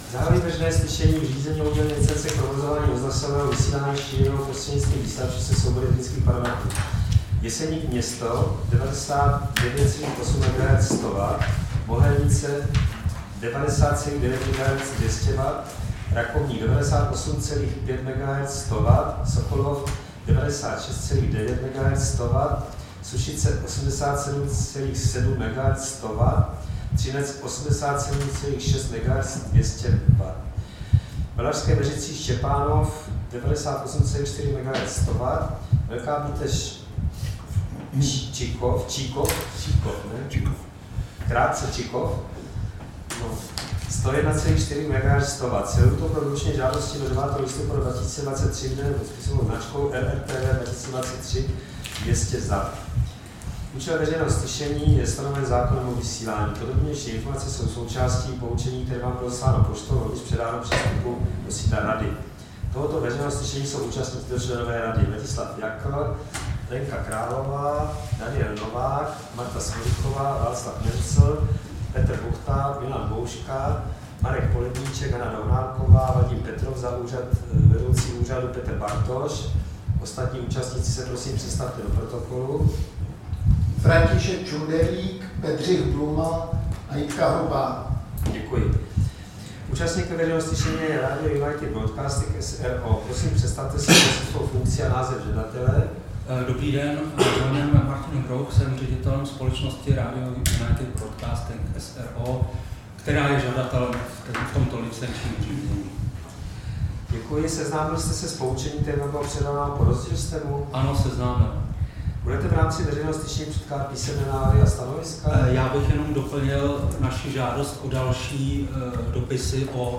Veřejné slyšení v řízení o udělení licence k provozování rozhlasového vysílání šířeného prostřednictvím vysílačů se soubory technických parametrů Jeseník-město 99,8 MHz/100 W, Mohelnice 90,9 MHz/200 W, Rakovník 98,5 MHz/100 W, Sokolov 96,9 MHz/100 W, Sušice 87,7 MHz/100 W, Třinec 87,6 MHz/200 W, Valašské Meziříčí-Štěpánov 98,4 MHz/100 W, Velká Bíteš-Čikov 101,4 MHz/100 W
Místem konání veřejného slyšení je sídlo Rady pro rozhlasové a televizní vysílání, Škrétova 44/6, 120 00 Praha 2.